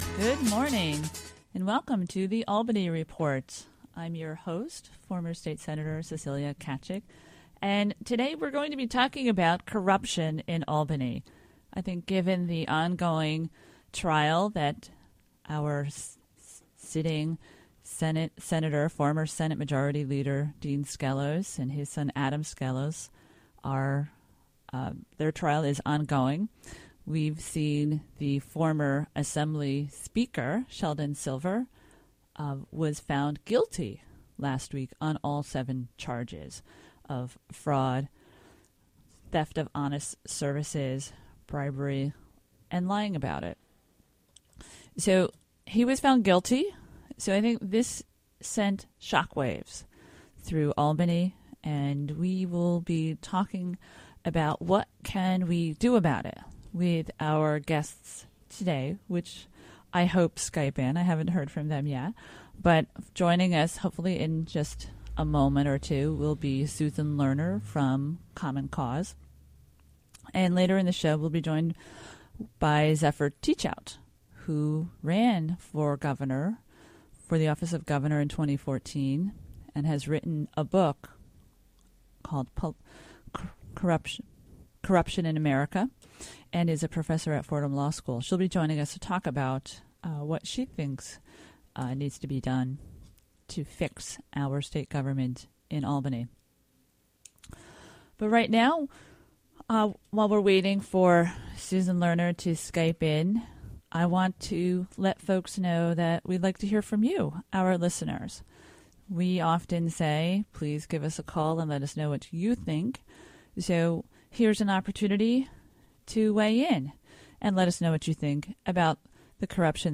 2015 shows The Albany Report Hosted by Cecilia Tkaczyk. broadcasts Zephyr Teachout